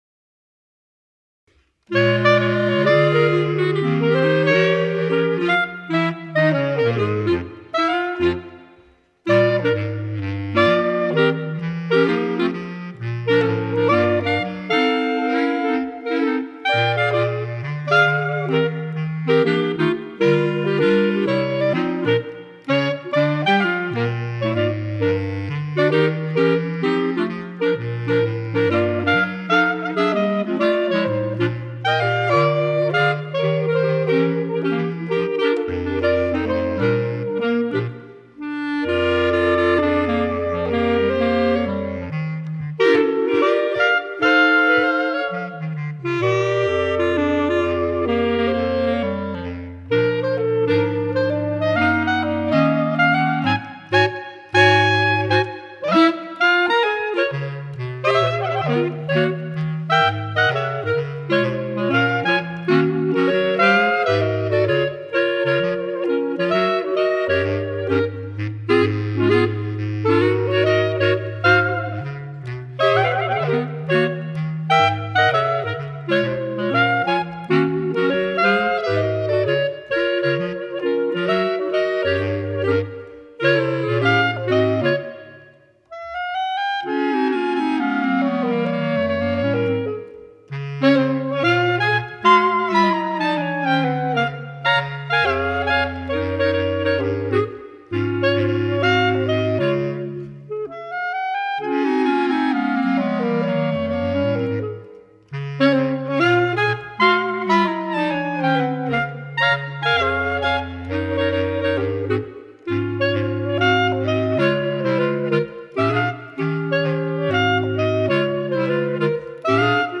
clarinet quartet
Bb Clarinet Range: E1 to D3. Bass Clarinet Lowest Note: E1